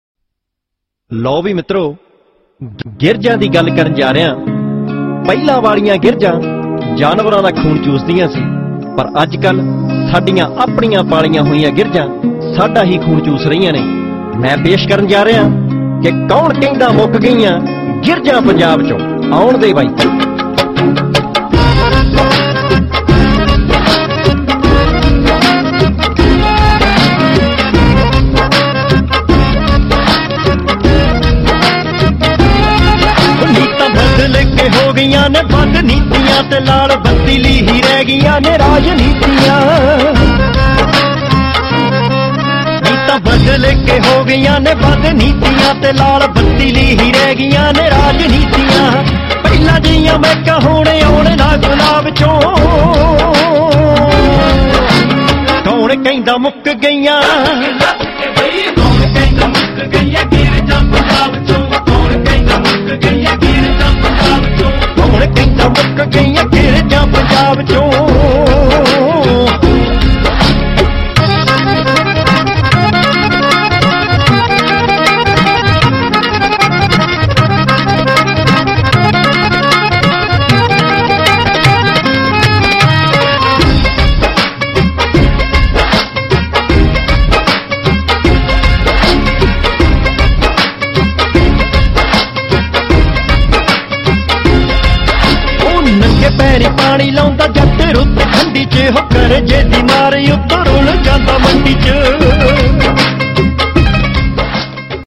Song Genre : Punjabi Old Song 2